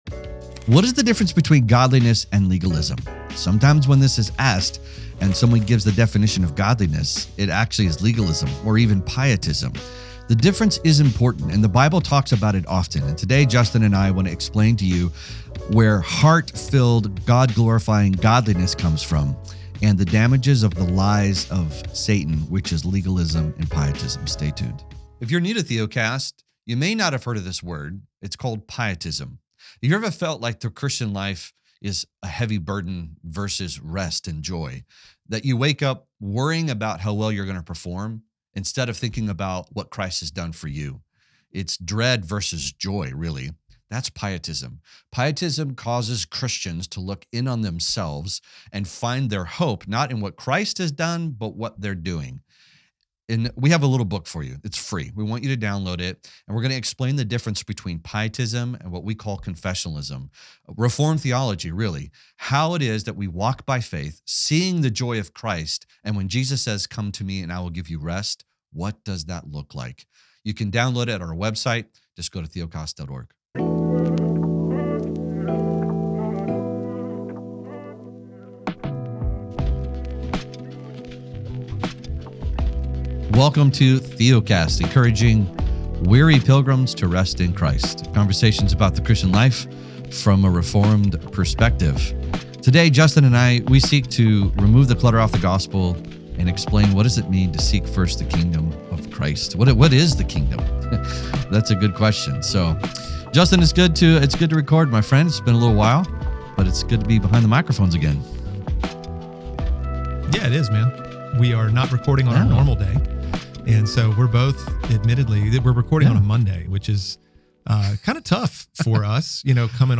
We pray you are encouraged by this conversation.